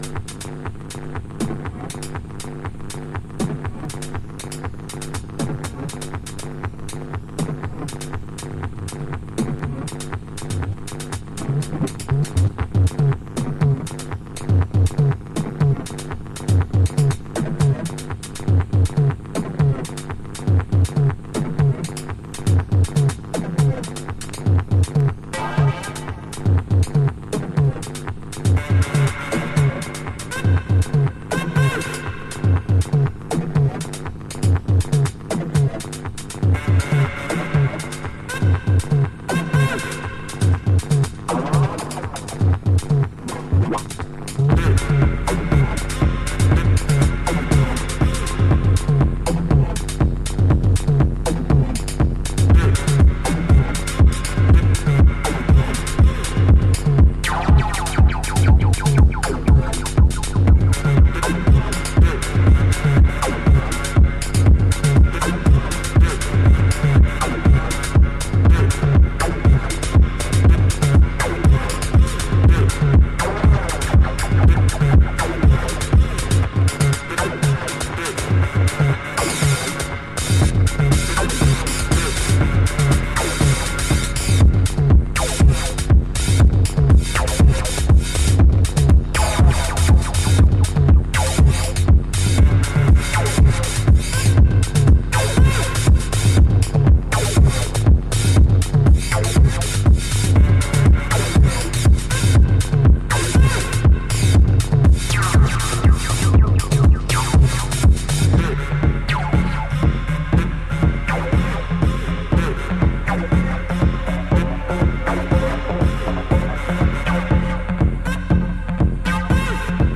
ボワボワのアブストラクト音響に変な叫び声とシンセを交差させたミュータントハウスA1。